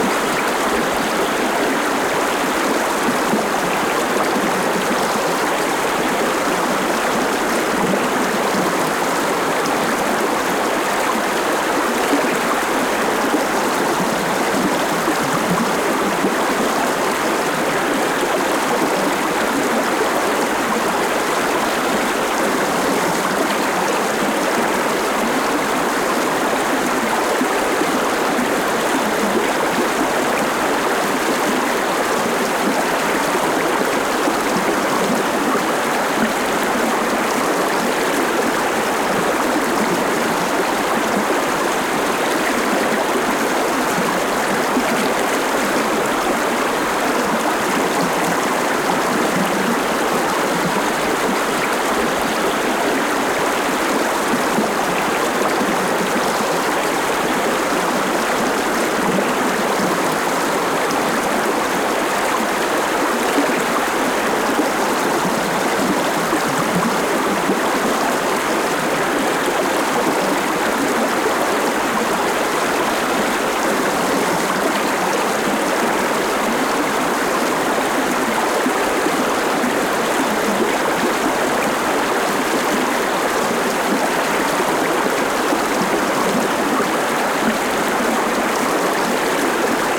water river Lyna medium flow close perspective and birds stereoORTF.ogg